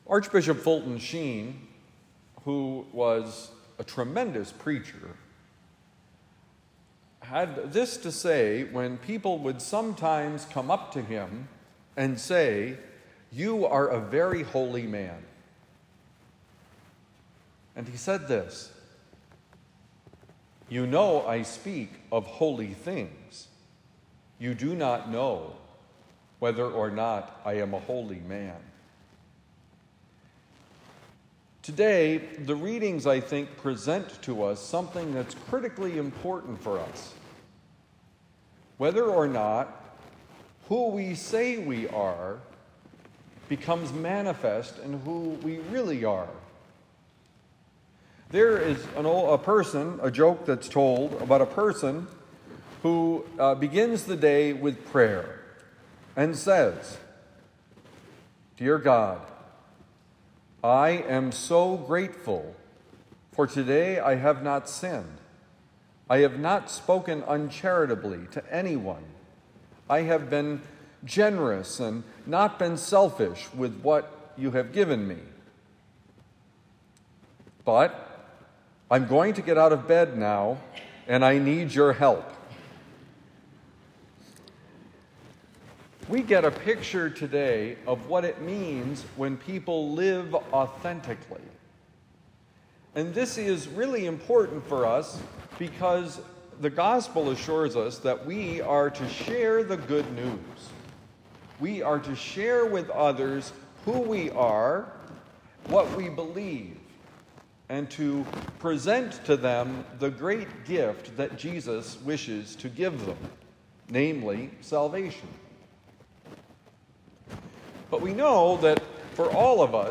Are we who we say we are?: Homily for Sunday, July 6, 2025 – The Friar